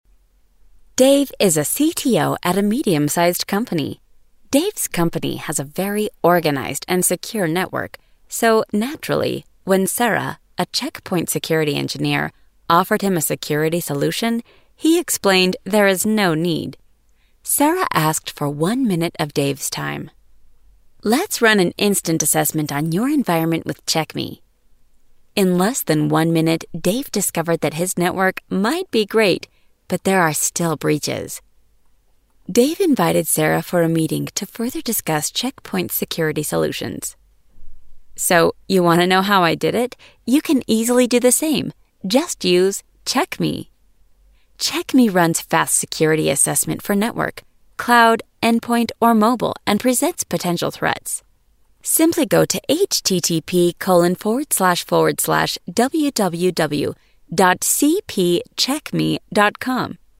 女英131 美式英语 有声读物 沉稳|娓娓道来|积极向上|亲切甜美|素人